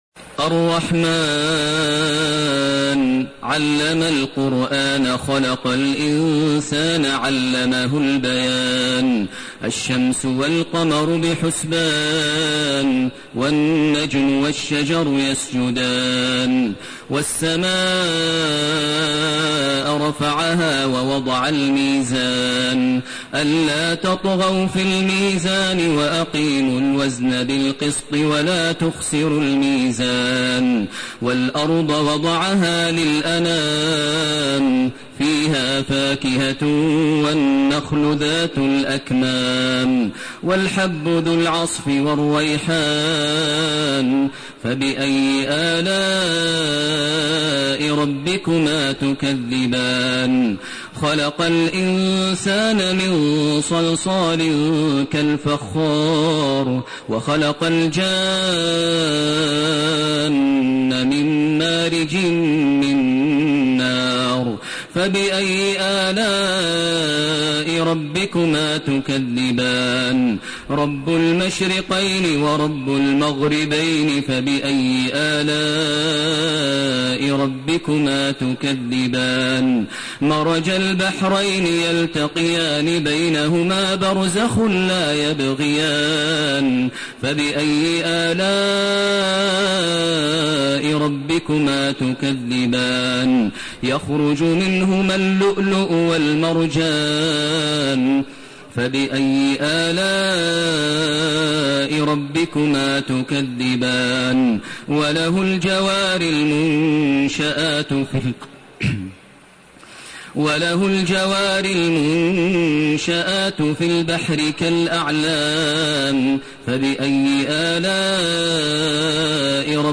سورة الرحمن سورة الواقعة سورة الحديد > تراويح ١٤٣٢ > التراويح - تلاوات ماهر المعيقلي